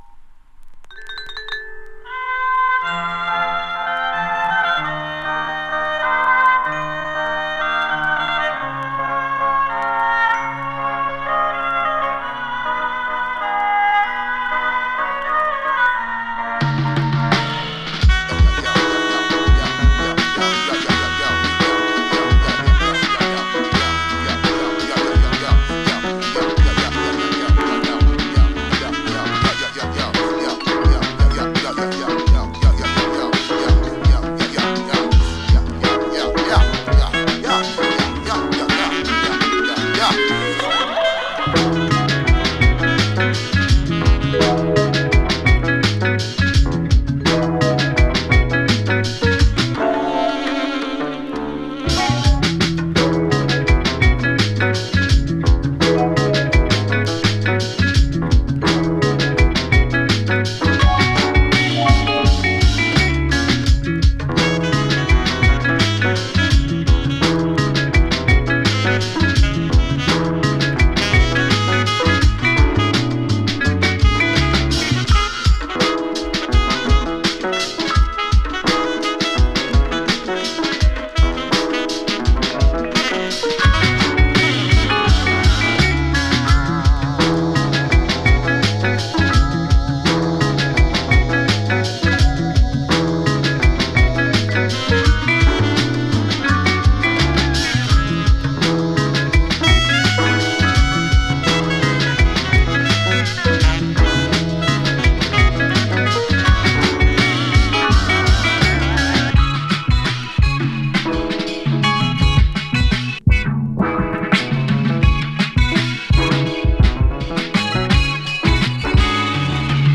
様々なリズム音楽の影響を受け、インストゥルメンタル・ミュージックに様々な音楽要素をミックス。